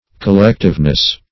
Collectiveness \Col*lect"ive*ness\, n.
collectiveness.mp3